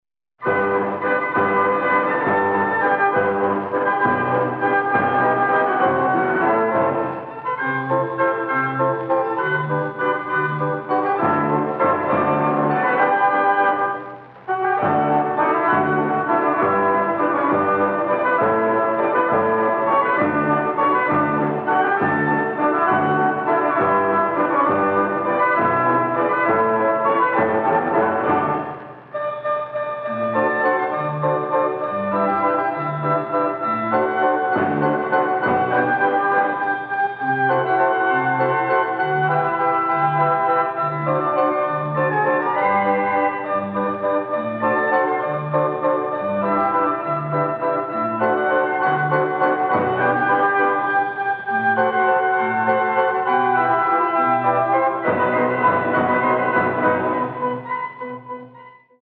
Reproduced from rare 78 RPM records